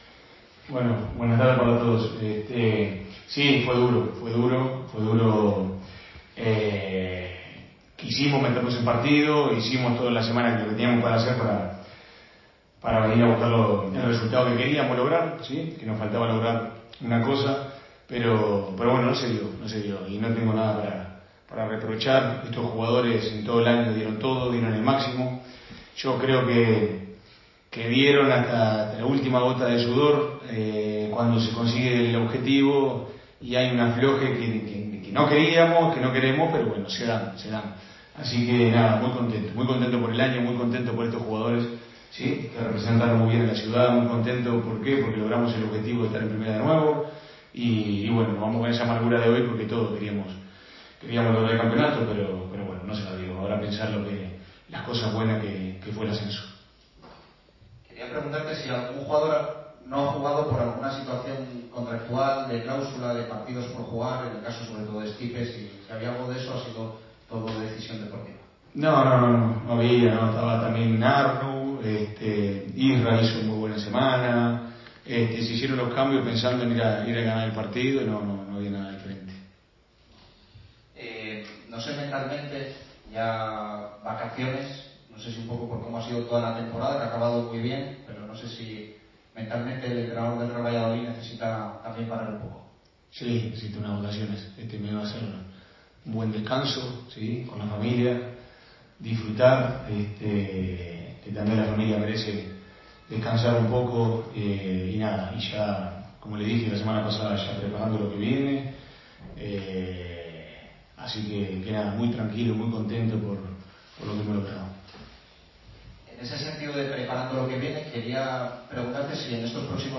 Ruedas de prensa
aquí la rueda de prensa completa.